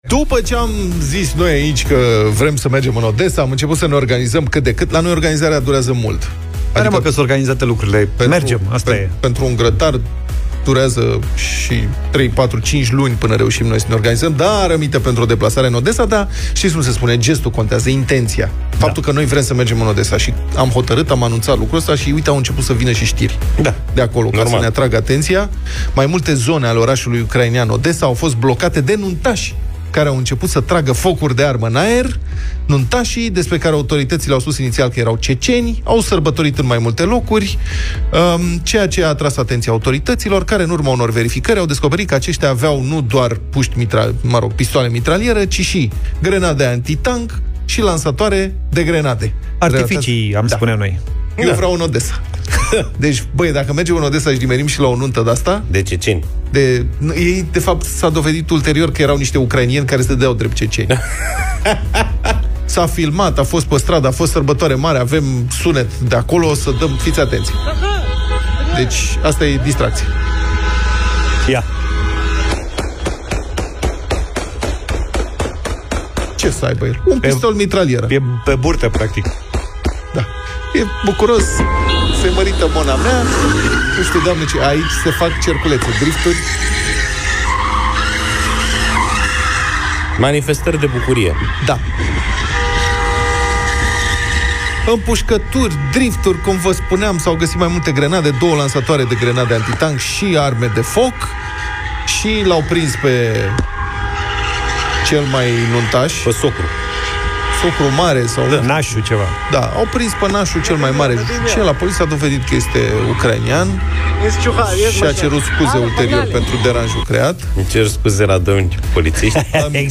au vorbit despre acest subiect în Deșteptarea